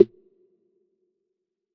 SNARE - UNDERWATER.wav